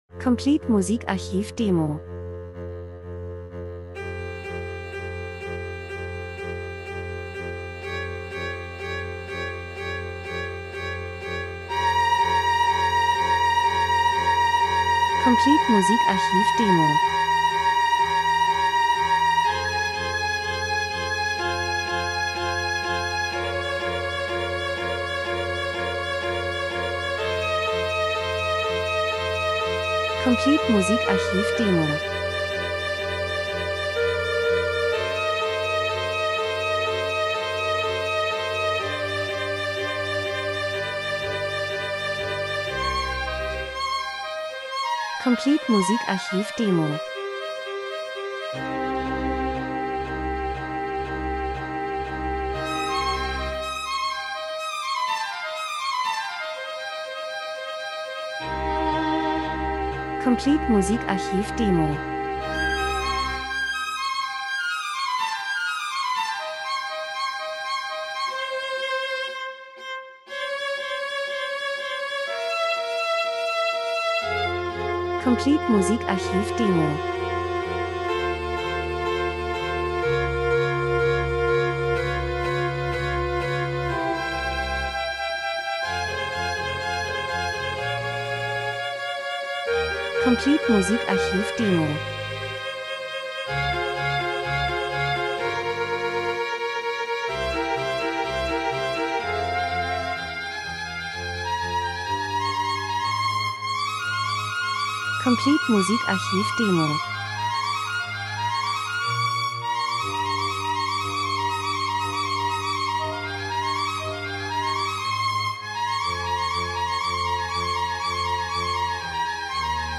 Streicherquartett
Wetterkapriolen Sturm Schneetreiben Dramatik   04:06